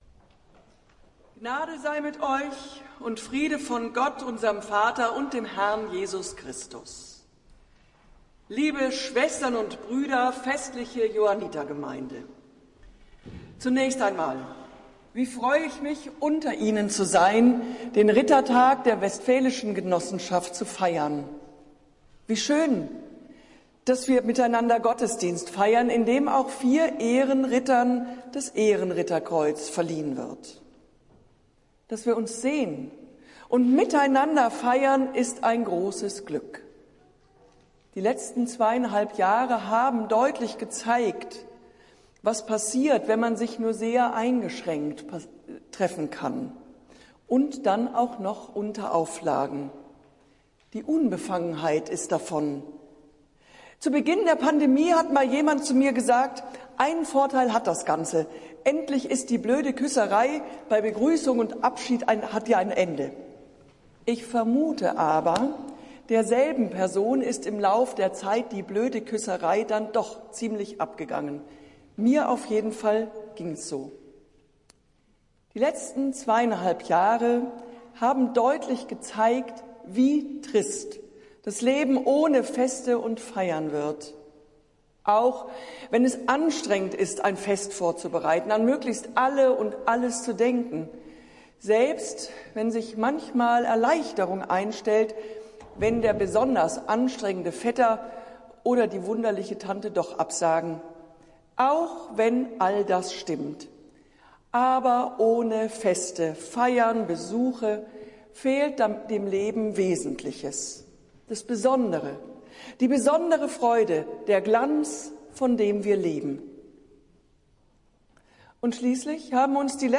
Predigt des Johanniter-Gottesdienstes aus der Zionskirche, vom Sonntag, den 4. September 2022